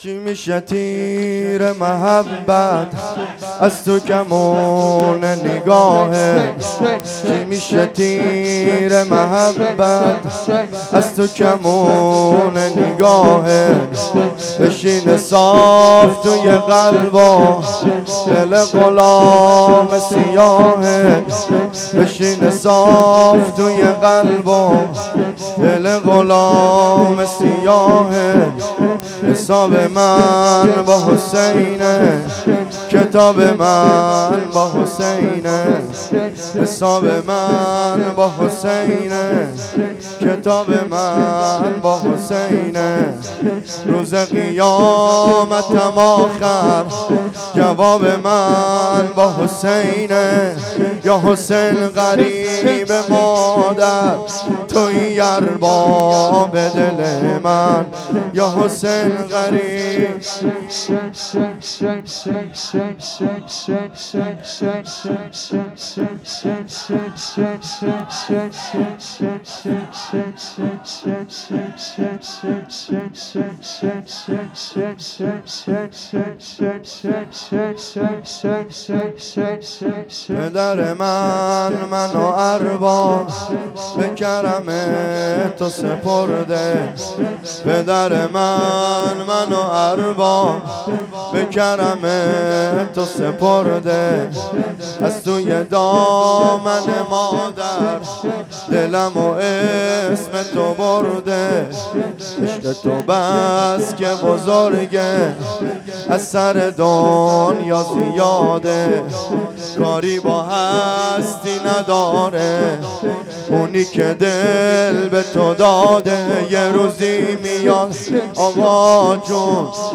شور
شب دوازدهم ماه محرم